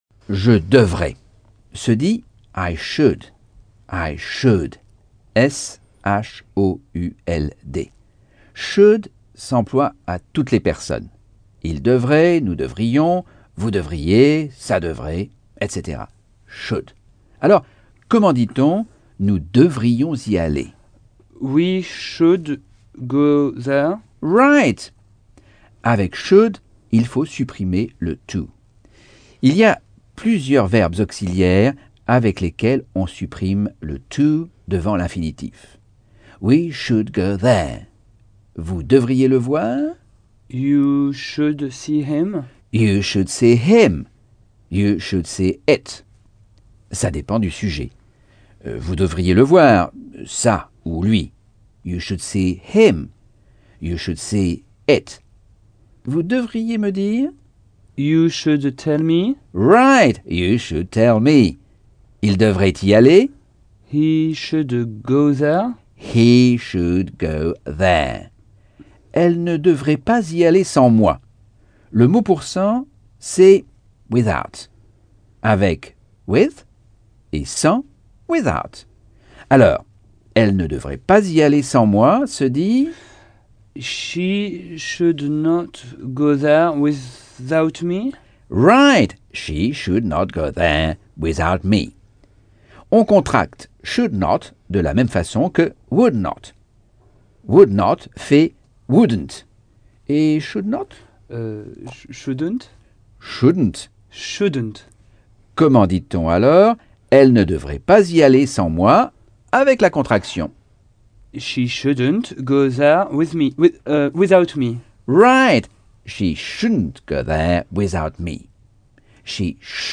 Leçon 3 - Cours audio Anglais par Michel Thomas